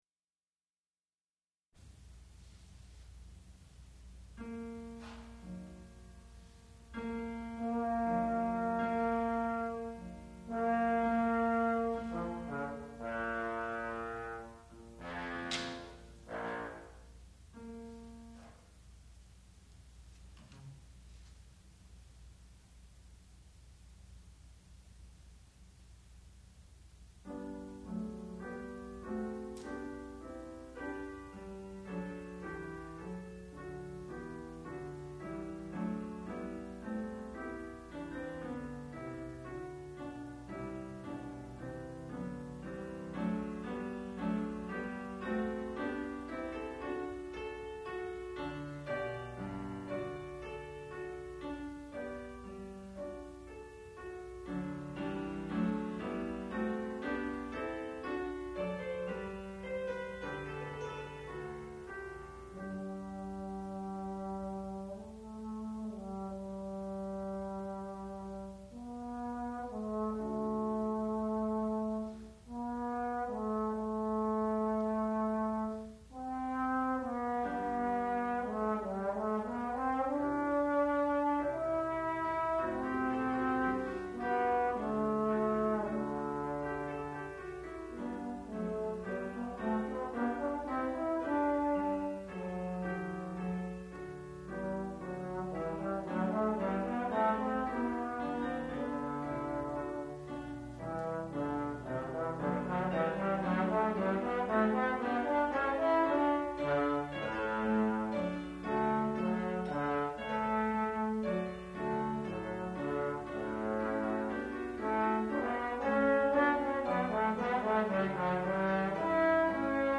sound recording-musical
classical music
bass trombone
piano
Graduate Recital